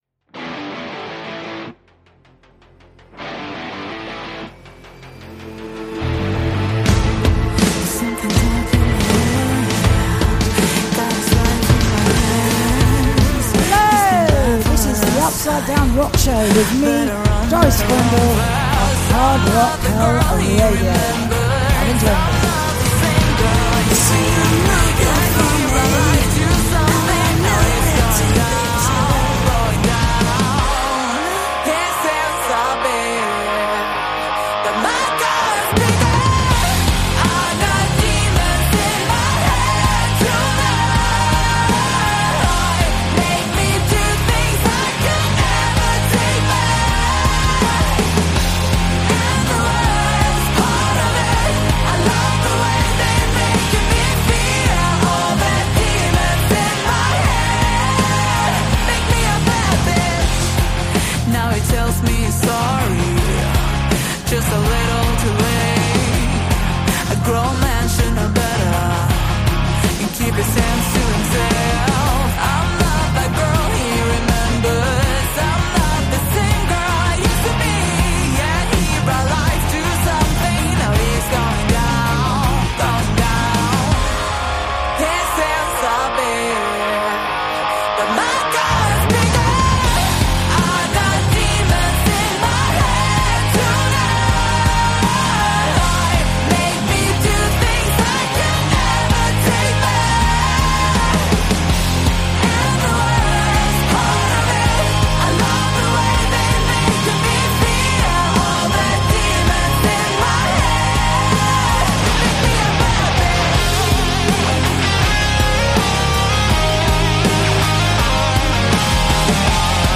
Plus a fabulous selection of new rock releases